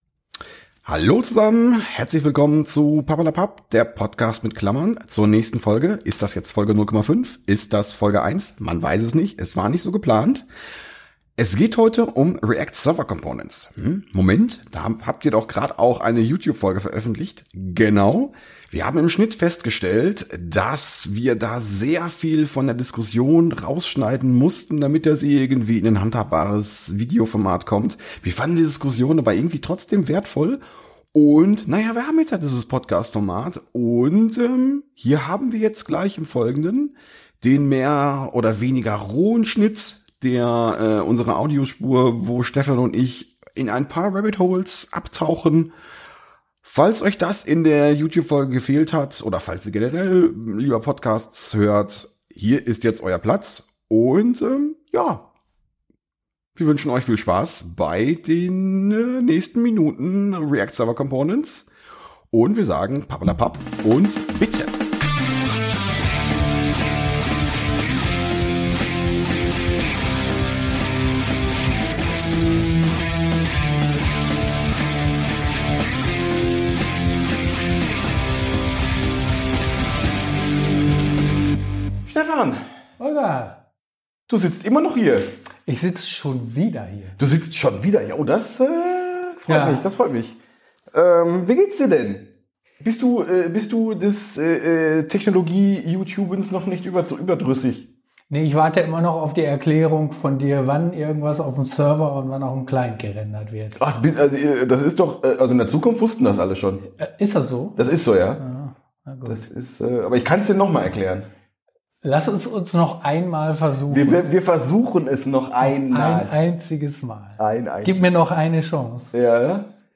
Dieser Podcast ist die längere ungeschnittenere Variante unseres Videos zu dem Thema. Wir fanden die ausschweifende Diskussion zu spannend, um sie wegzuwerfen.